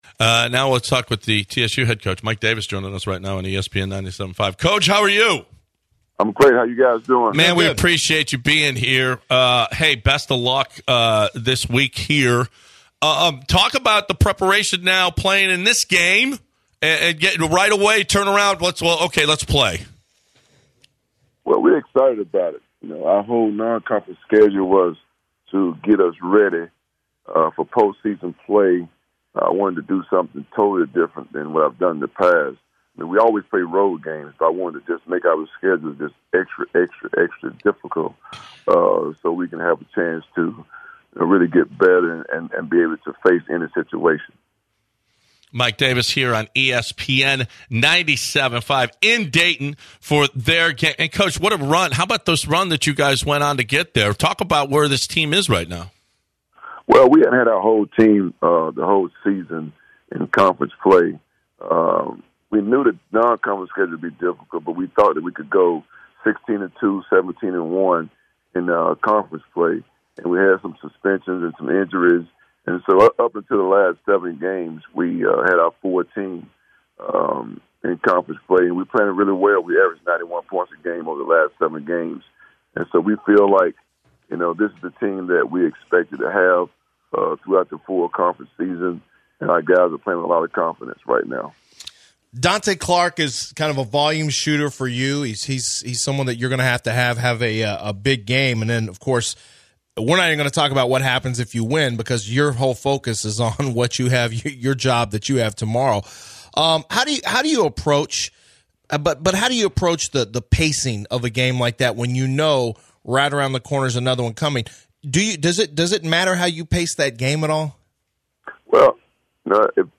The guys talk with TSU head coach Mike Davis, discussing TSU's upcoming game against North Carolina Central